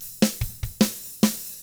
146ROCK F1-R.wav